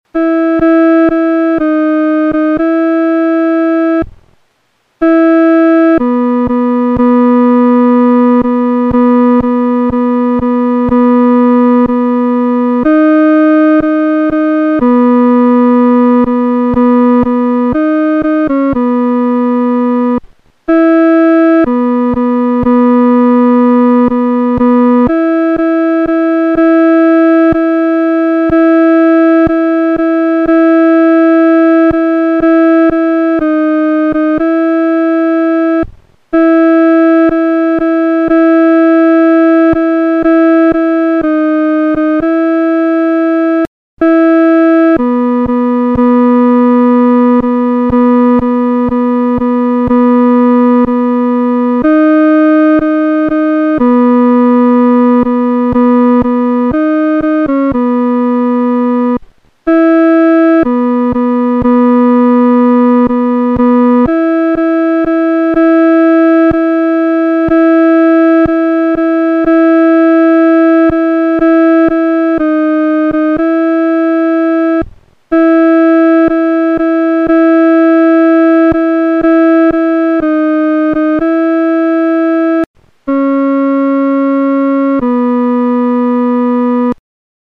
女低伴奏